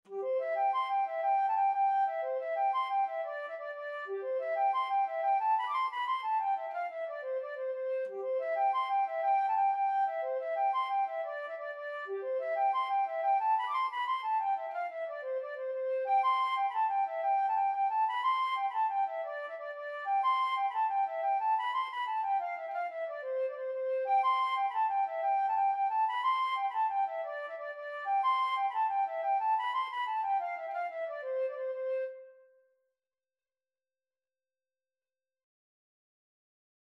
6/8 (View more 6/8 Music)
G5-D7
C major (Sounding Pitch) (View more C major Music for Flute )
Flute  (View more Easy Flute Music)
Traditional (View more Traditional Flute Music)